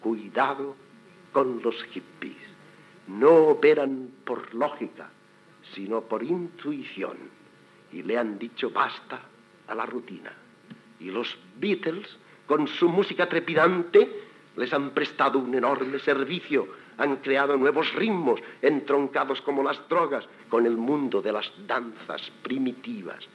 Comentari sobre els hippies i el grup musical The Beatles